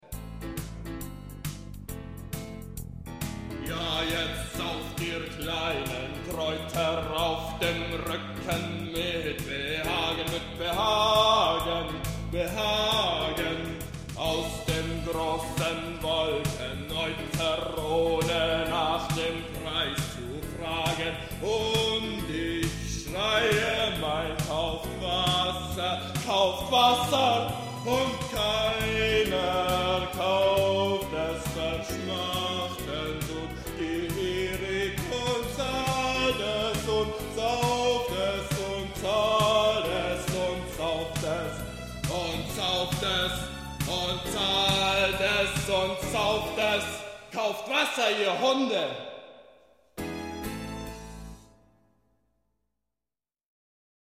klavierlieder
[musical-version]